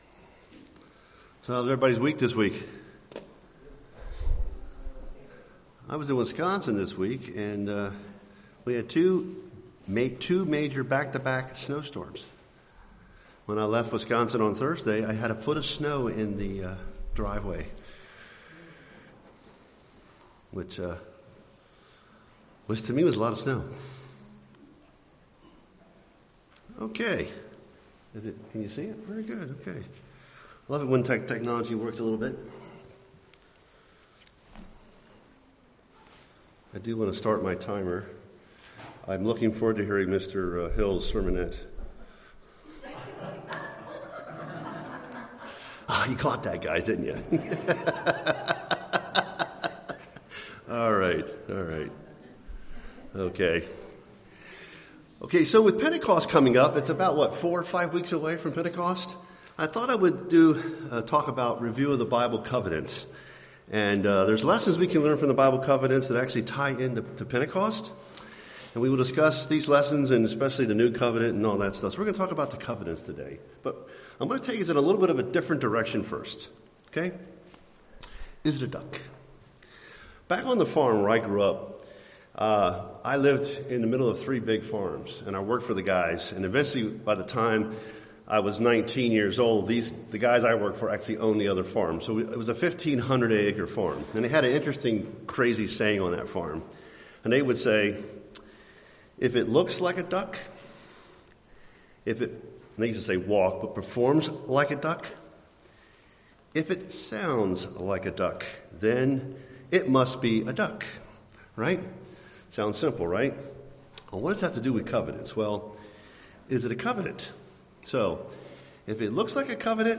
Listen to this sermon to learn about God's building of a covenant relationship with His disciples.
Given in San Jose, CA